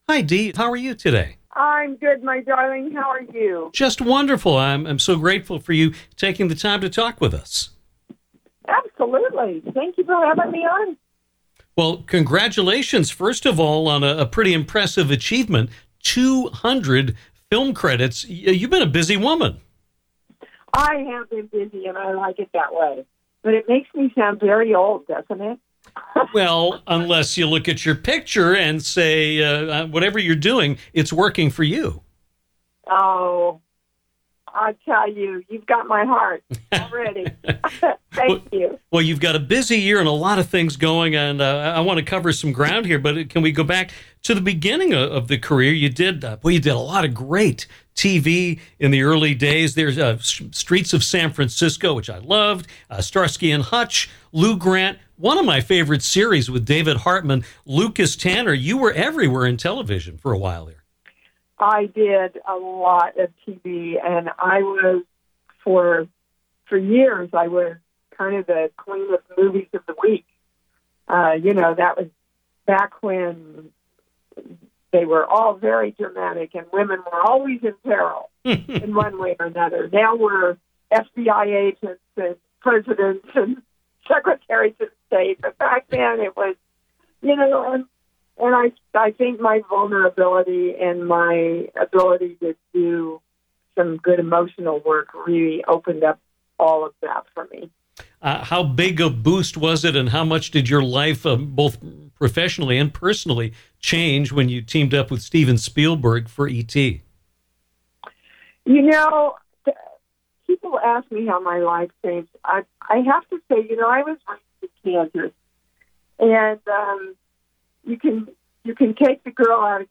Actor, radio host, and motivational speaker Dee Wallace joined us on Downtown to talk about her career, which now includes 200 film credits, more than any living actress.